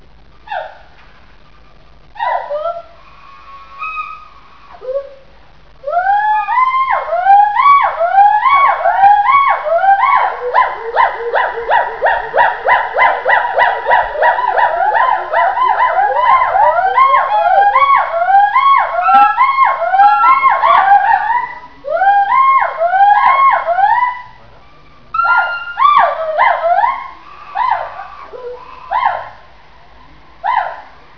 Short phrases of bi-phasic hoots ("ow-wa"), simple hoots, high pitched eeks, and low pitched growls.
Female great call with an acceleration-type climax, like H. moloch, of moderate speed, usually becoming slower near end.
Mated pairs typically produce duet song bouts only.
Press to start sound Group song, Kunming Zoo, China, 27 Juli 1990.
Although most of the singing is provided by the subadult male "Jian-Jian" and the adult female "Gui-Gui" (singing in adjacent cages), several other immature hoolocks also contributed to the song bout.